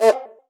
BCUICA LW.wav